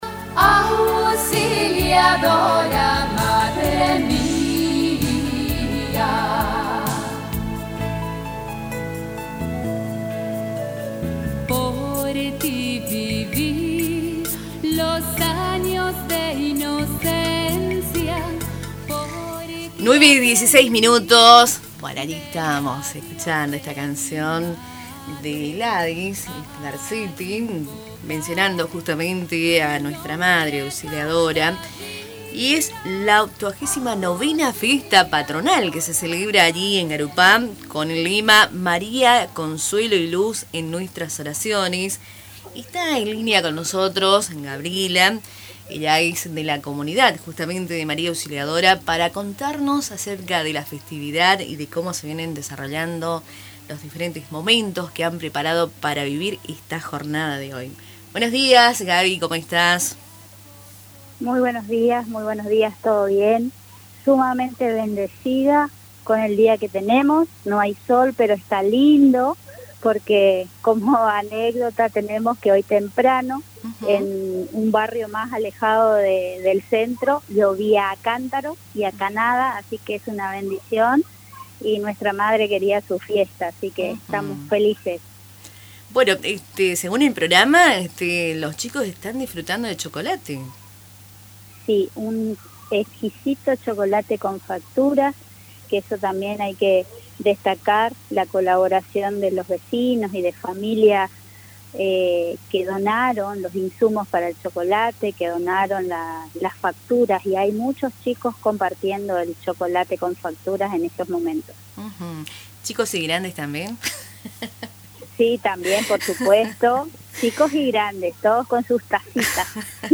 En una entrevista con Radio Tupa Mbae